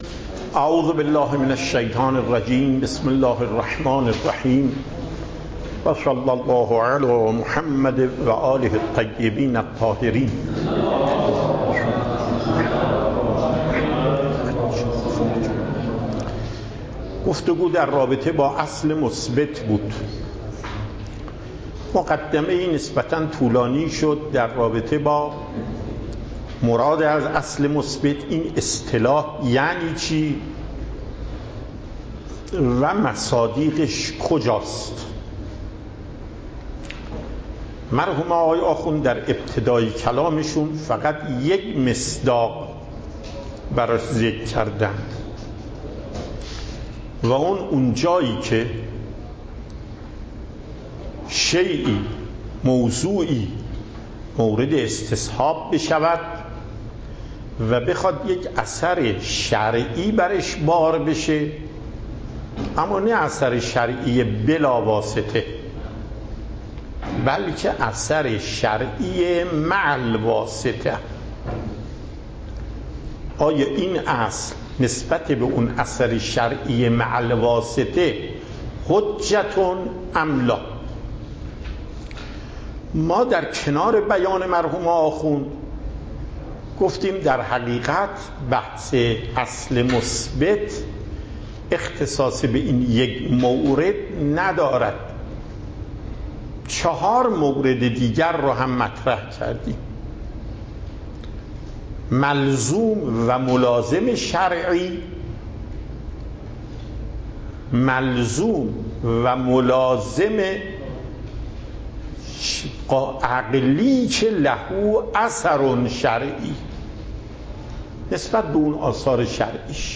صوت درس
درس اصول آیت الله محقق داماد